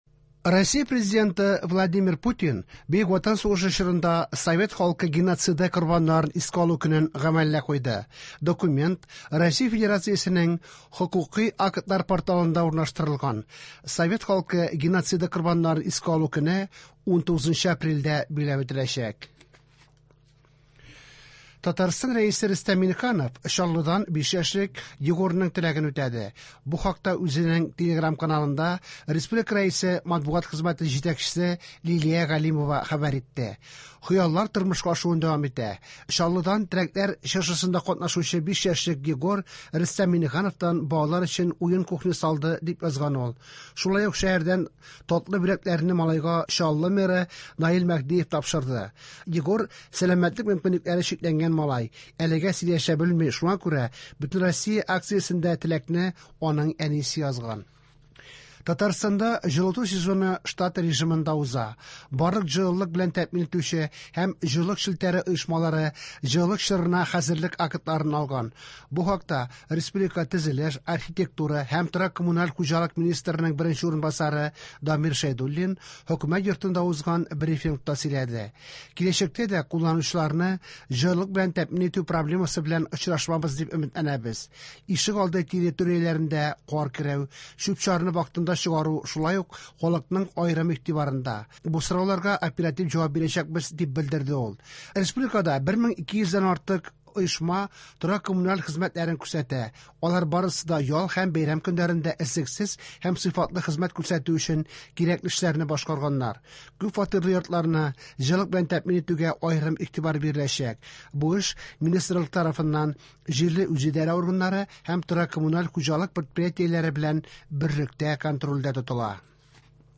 Яңалыклар (29.12.25)